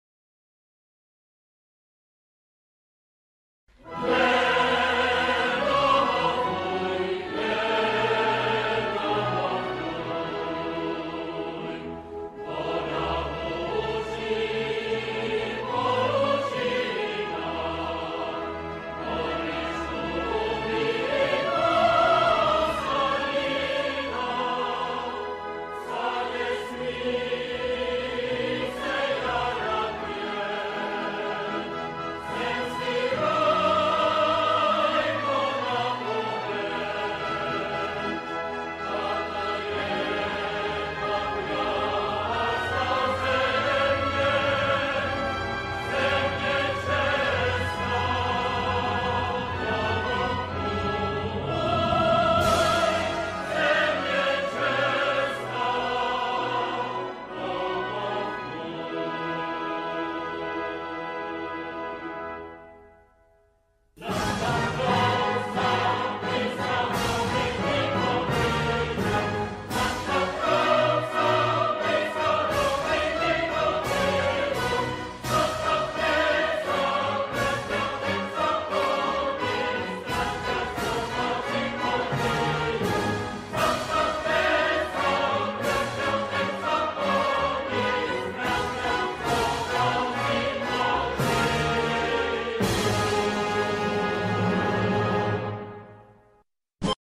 Lagu ini adalah Lagu Kebangsaan Negara Cekoslovakia. Uniknya, mereka memainkan kedua Lagu Kebangsaan (Ceko yang diwakili Lagu "Kde domov můj", dan Slovakia di wakili lagu "Nad Tatrou sa blýska"). Lagu ini dipakai selama Negara Cekoslovakia dari tahun 1918, Hingga berpisahnya Slovakia dengan damai pada tahun 1992.